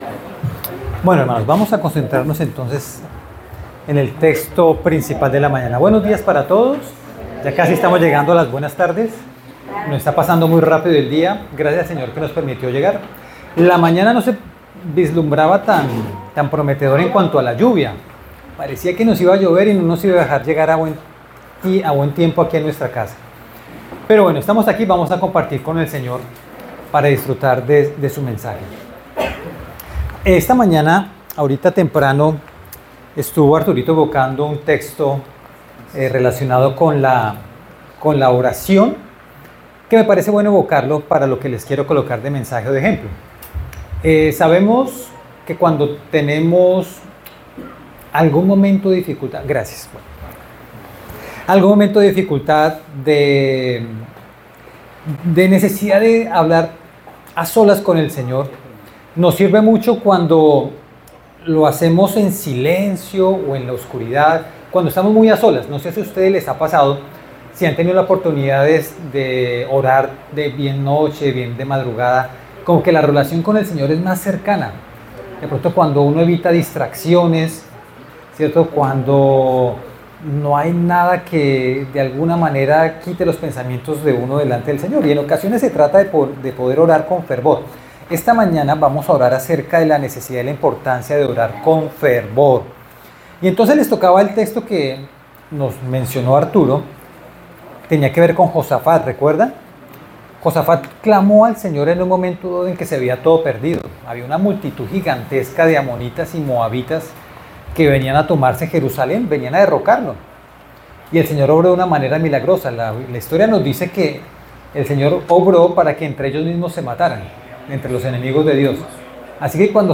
Predicaciones Primer Semestre 2025